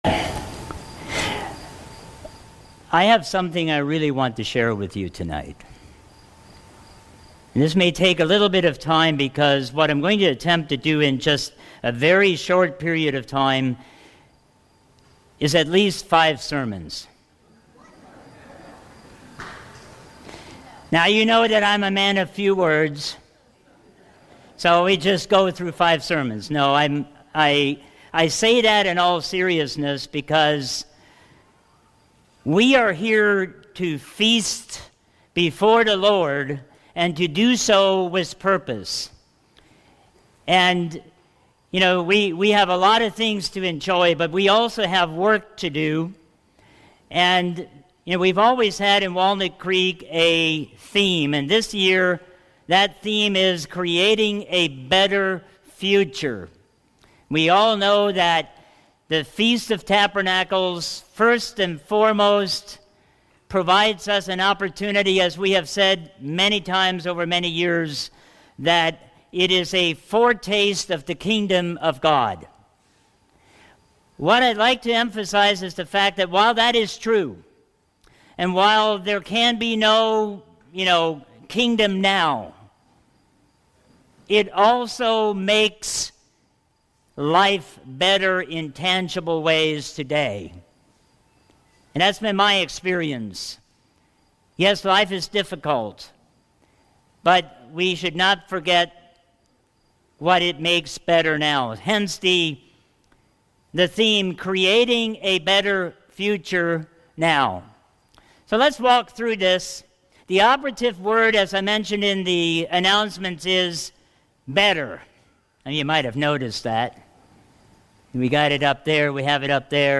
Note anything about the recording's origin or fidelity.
Better sacrifices, promises, covenant, hope, and resurrection. Message given on opening night at the Feast of Tabernacles in Walnut Creek, Ohio.